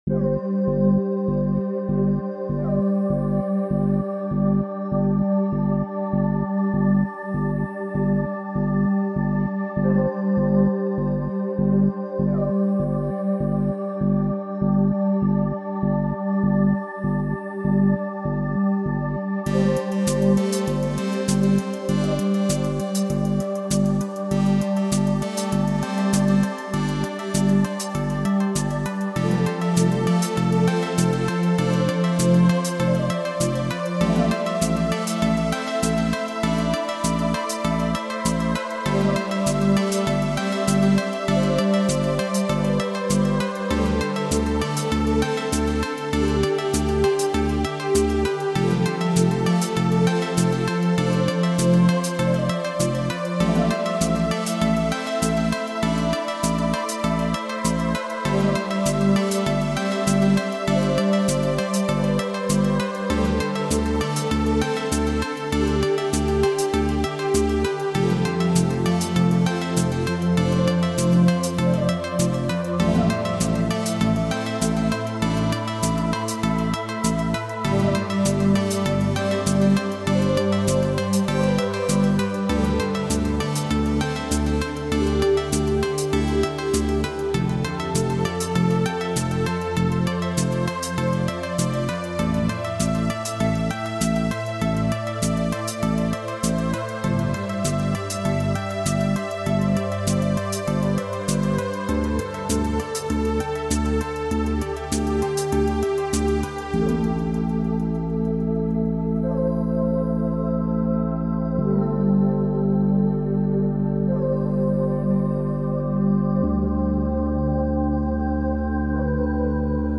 And chillout track really fits to her learning process.